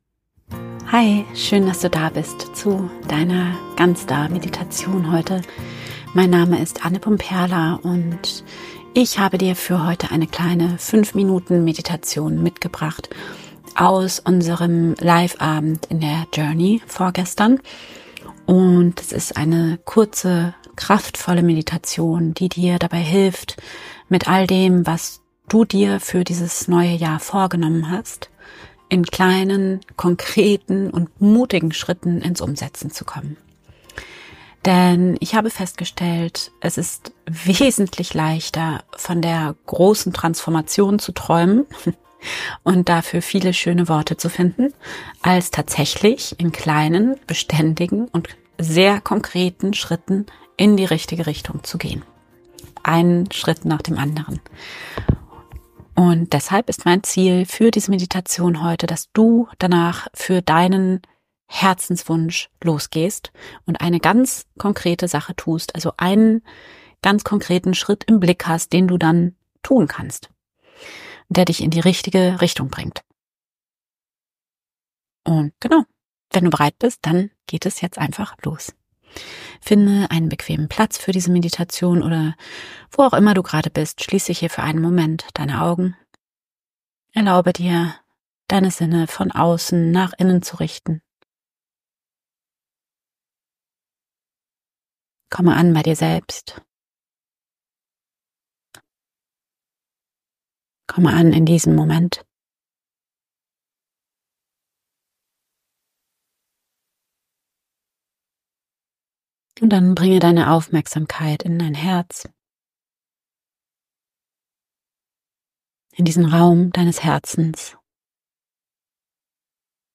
Mit dieser kurzen Meditation kommst du endlich mutig ins Handeln...